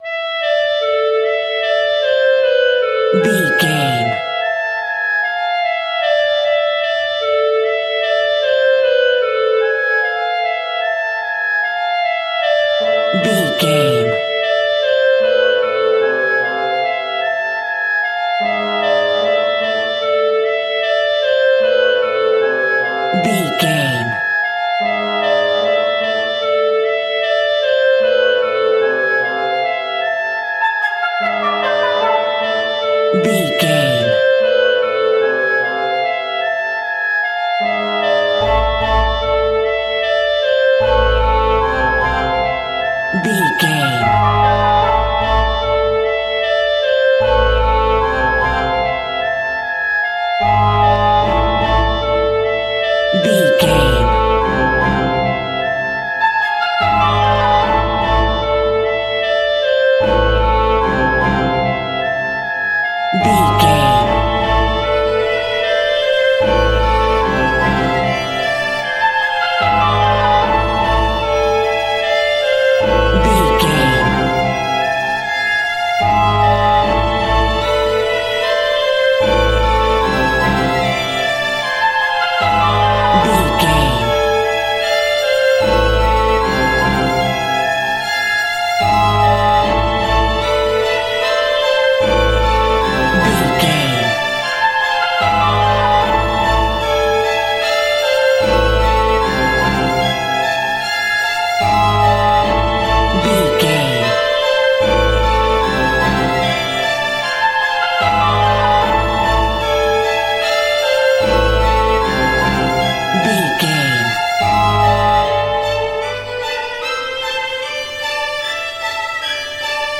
Classical Horror.
Aeolian/Minor
ominous
dark
suspense
eerie
synthesiser
strings
creepy
ambience
pads